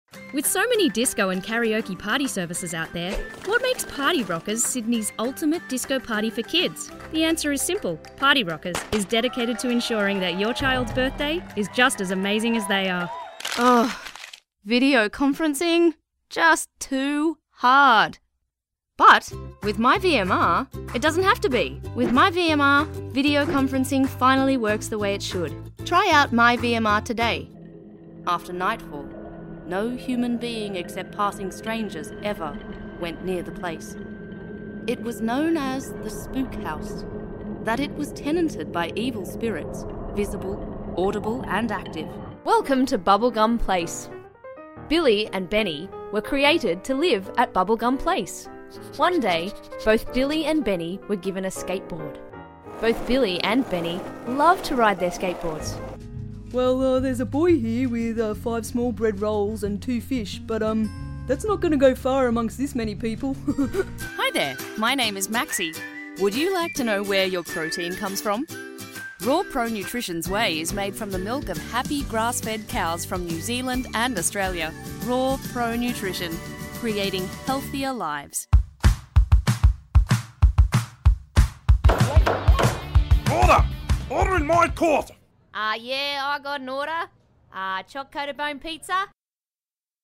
Female
English (Australian)
Adult (30-50)
Main Demo